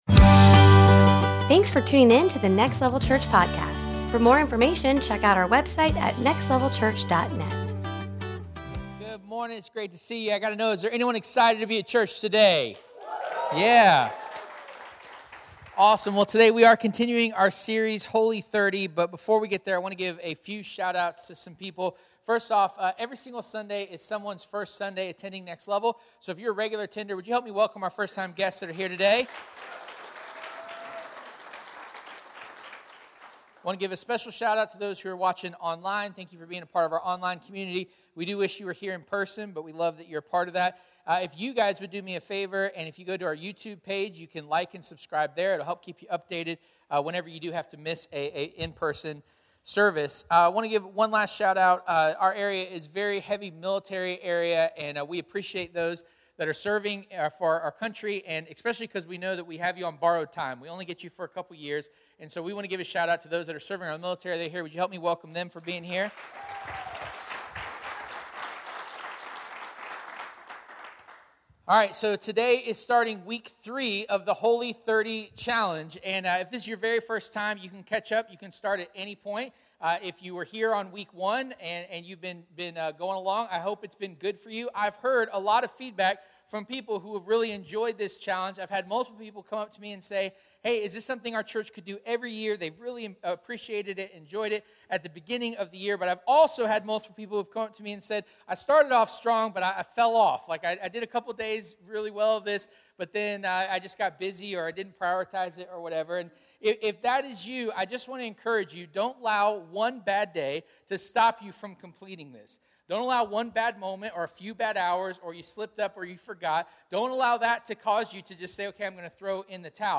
Service Type: Sunday Morning Holy 30 is a 30-day challenge to ignite your faith and focus on God!